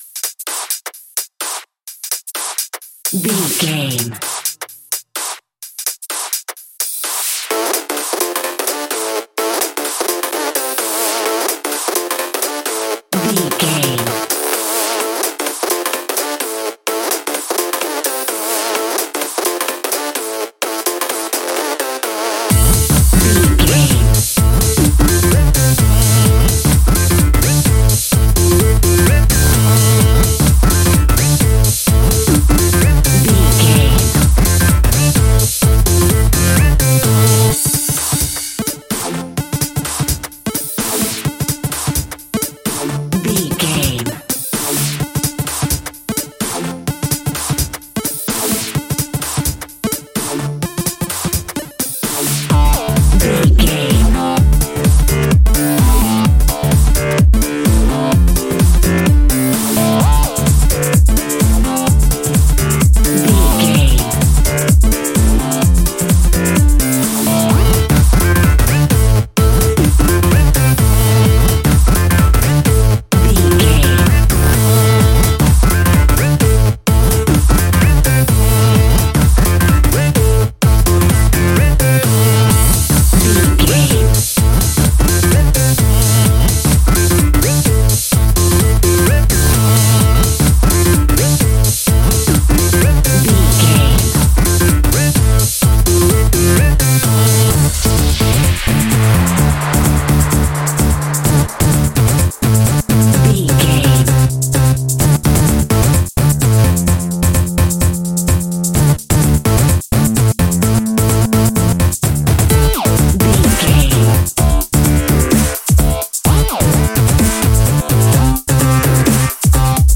Aeolian/Minor
groovy
driving
energetic
hypnotic
drum machine
synthesiser
deep house
nu disco
upbeat
funky guitar
clavinet
synth bass
horns